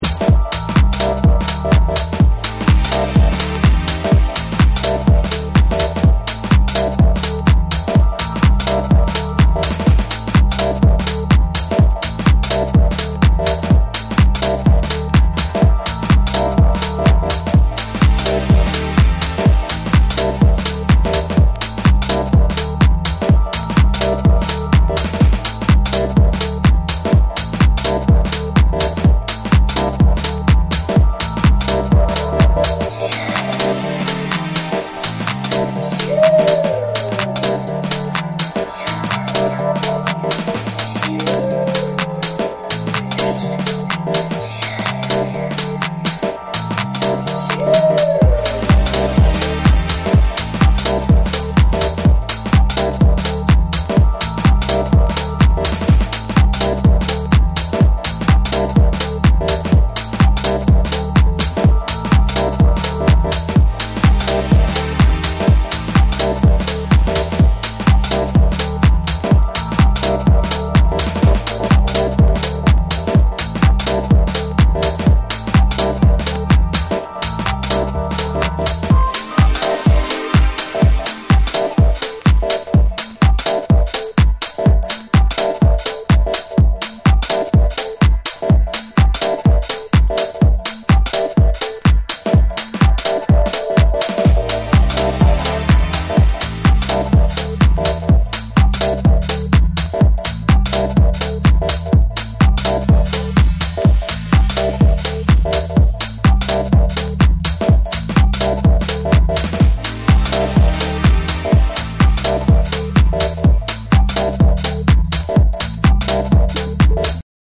空間を巧みに使ったディープ・グルーヴとミニマルな展開が光るフロア仕様の4トラックを収録。
TECHNO & HOUSE